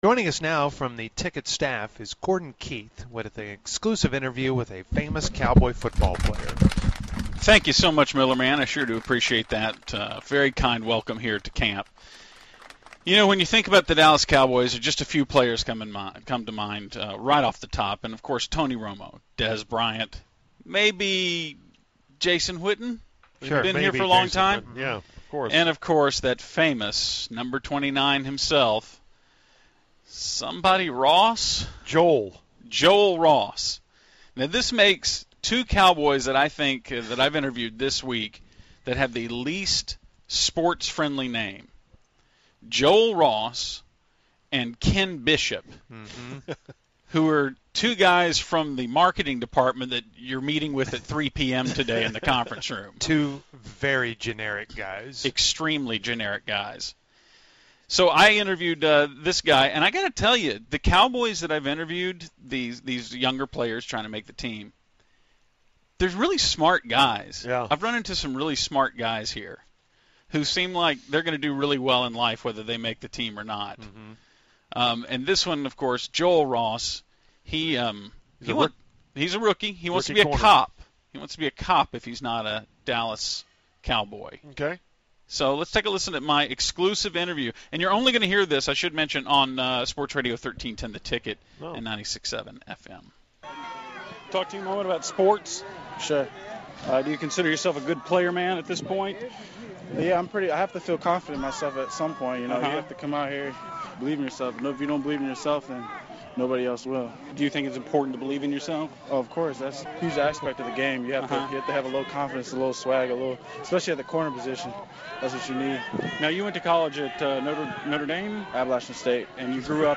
an awkward conversation